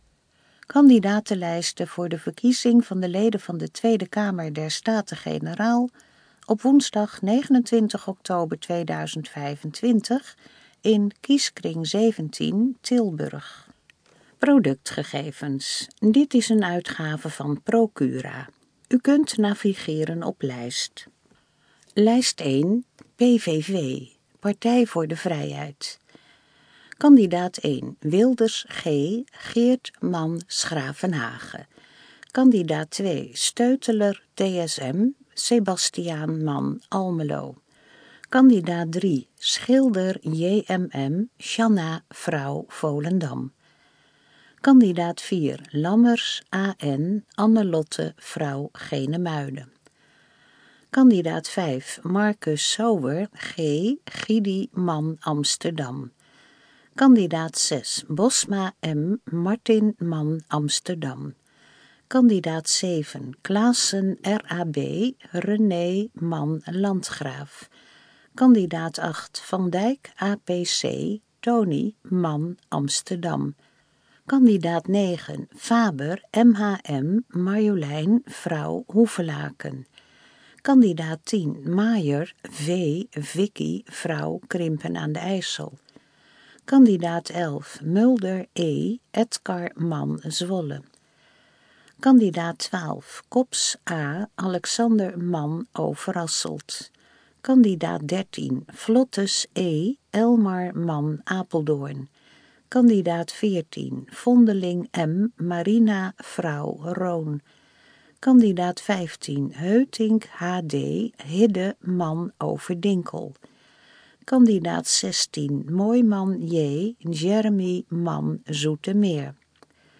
Gesproken kandidatenlijst Tweede Kamerverkiezingen 2025 | Gemeente Geertruidenberg
gesproken_kandidatenlijst_tweede_kamerverkiezingen_2025.mp3